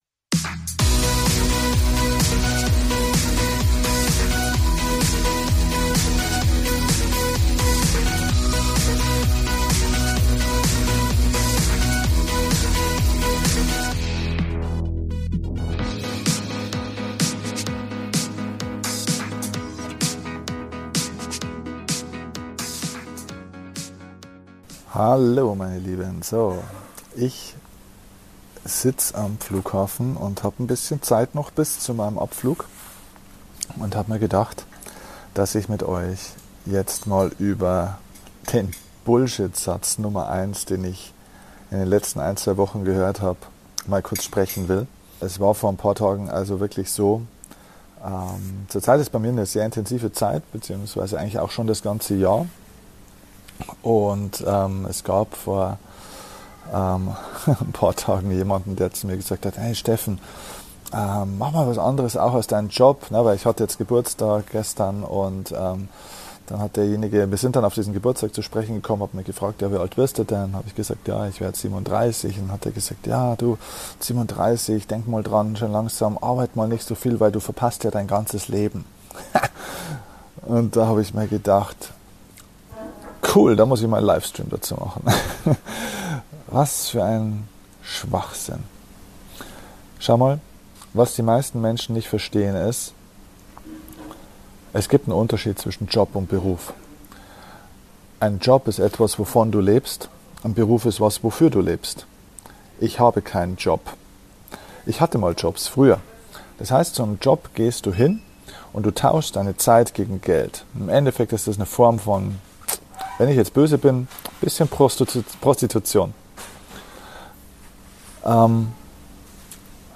In dieser Folge erzähle ich Dir von einem Satz, der mir zum Geburtstag gesagt wurde und der einer der dümmsten Sätze überhaupt ist! Diese Folge ist wieder einmal ein Audiomitschnitt eines Livestreams auf Facebook ist.